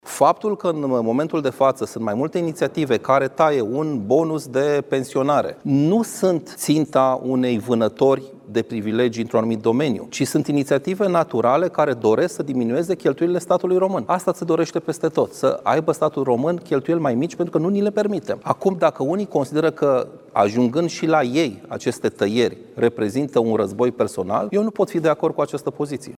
Liderul senatorilor USR, Ștefan Pălărie, a declarat că aceste cheltuieli nu erau justificate.
Liderul senatorilor USR, Ștefan Pălărie: „Asta se dorește peste tot: să aibă statul român cheltuieli mai mici”